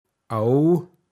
pinzgauer mundart
ab- (Vorsilbe) åo